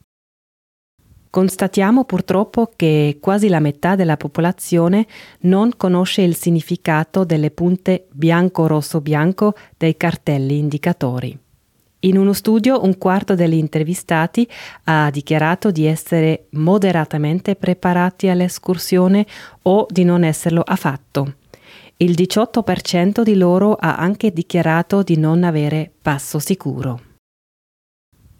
portavoce